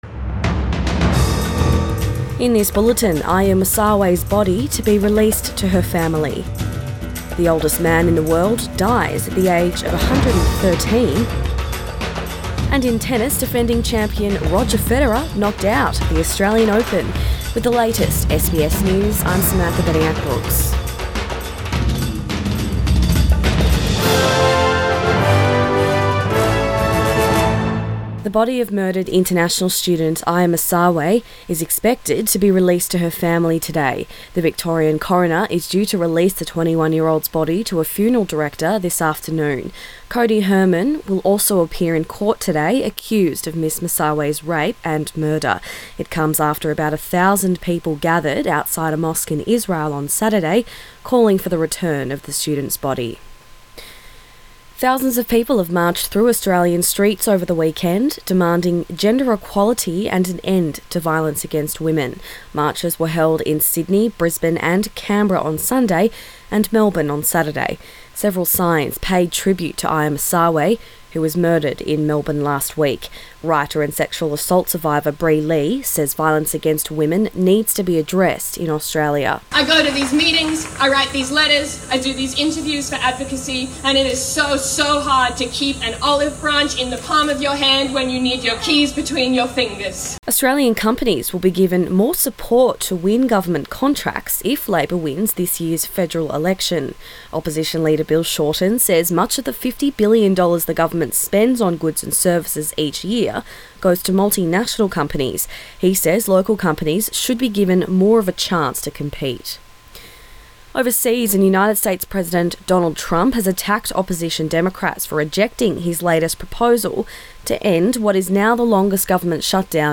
AM bulletin 21 January